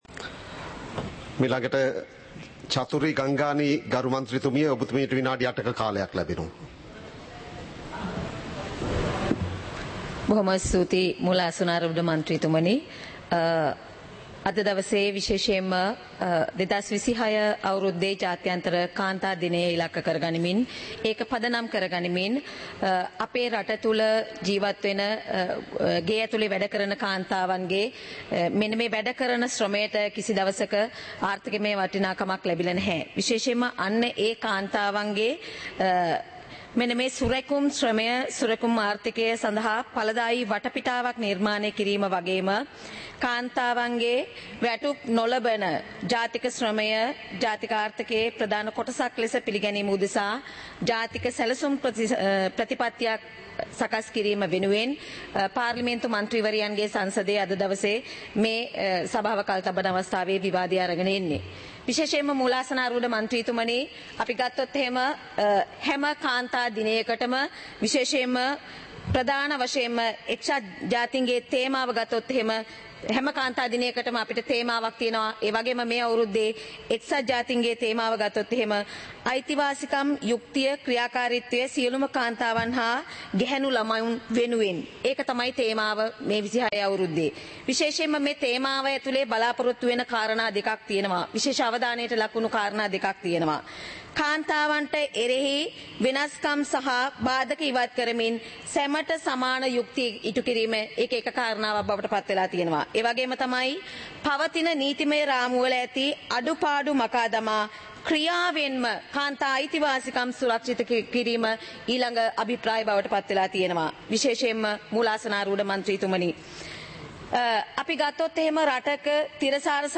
Proceedings of the House (2026-03-05)
Parliament Live - Recorded